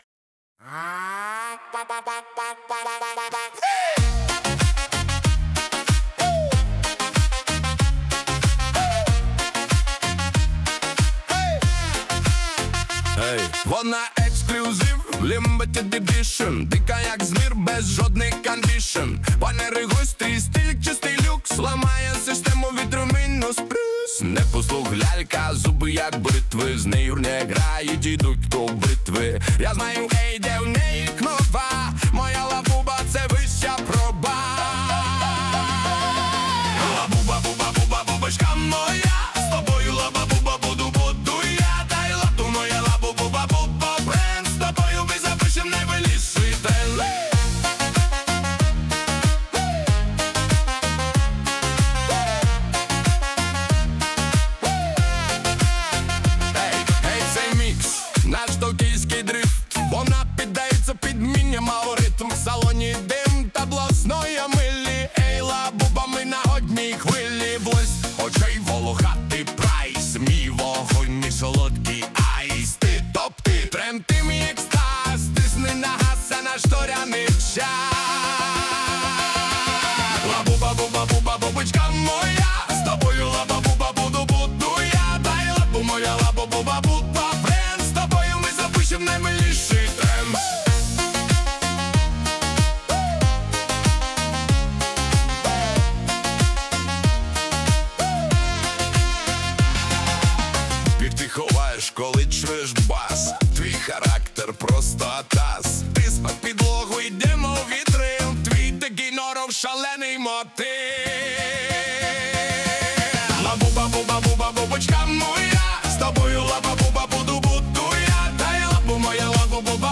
Свіжий стильний позитивний трек для гарного настрою
Стиль: Танцювальний